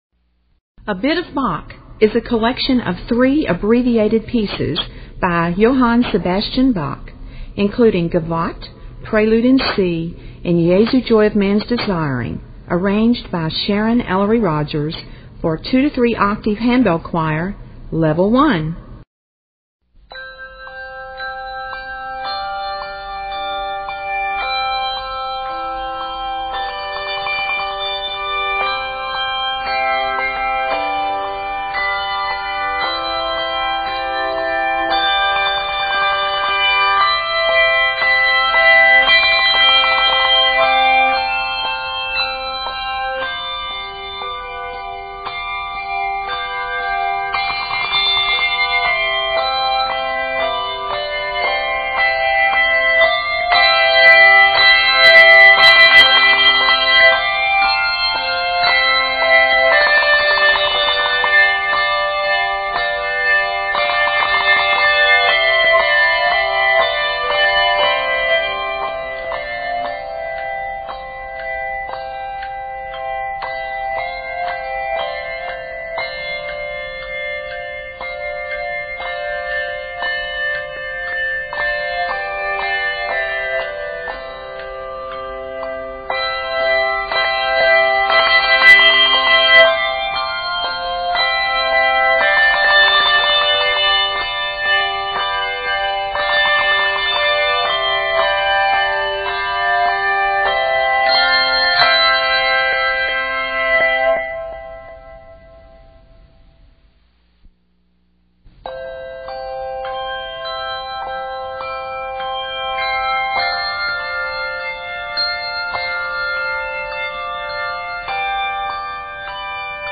The latter is set in 3/4 meter for ease of reading.